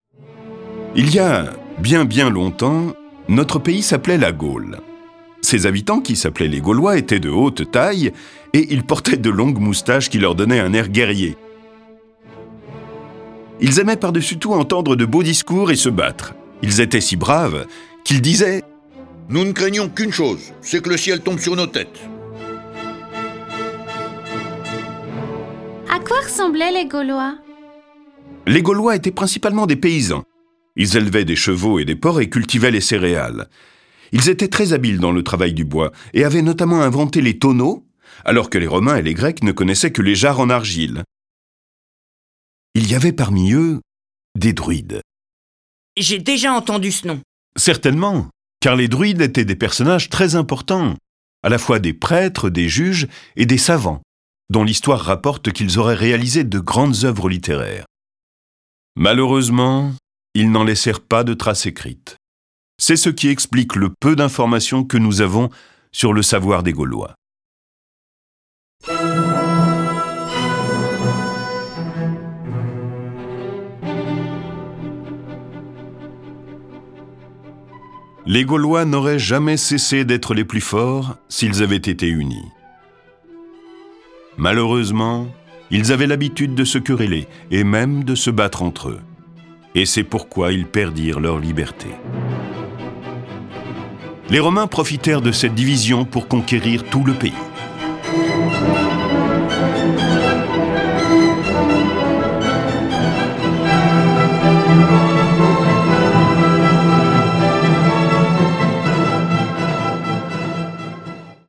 Diffusion distribution ebook et livre audio - Catalogue livres numériques
En vingt-cinq ans de règne, il fera émerger une Gaule nouvelle, moderne et baptisée. Découvrez la vie de Clovis, roi de la tribu des Francs saliens qui donnera son nom à notre pays. Cette version sonore de ce récit est animée par sept voix et accompagnée de plus de trente morceaux de musique classique.